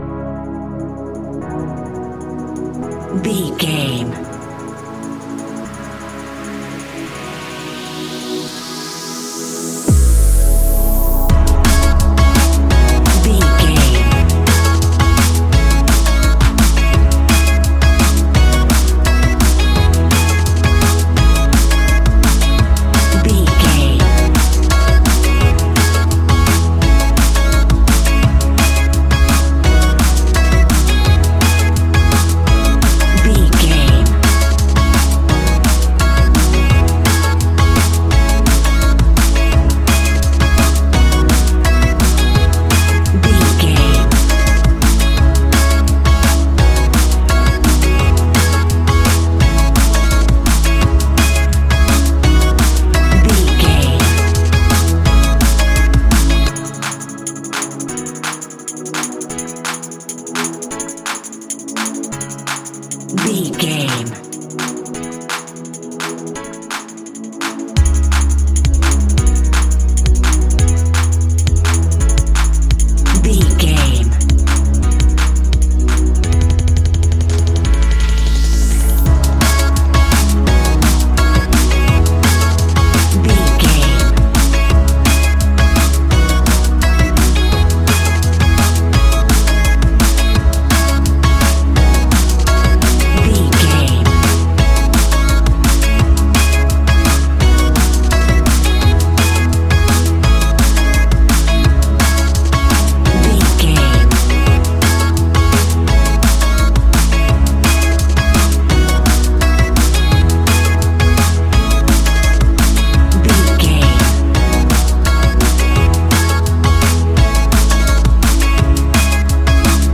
Ionian/Major
electronic
techno
trance
synths
synthwave